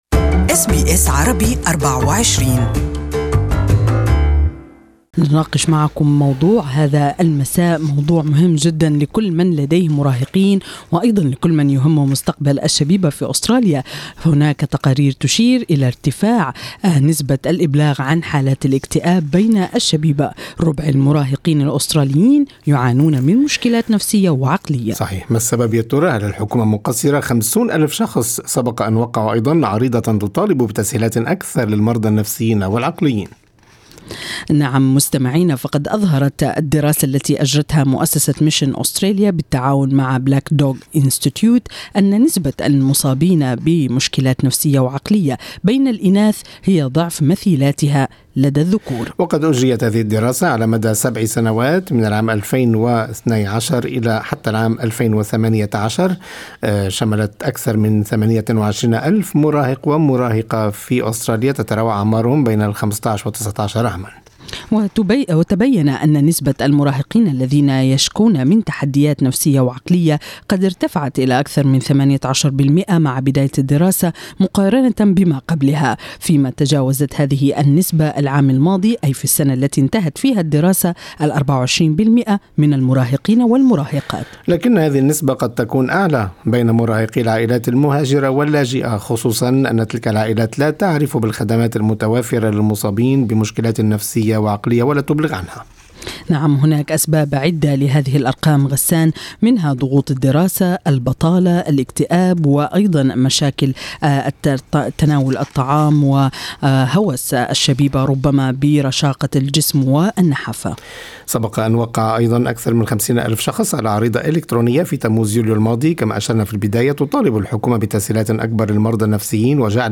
المزيد عن هذا الموضوع في لقاء مع أخصائي الصحة النفسية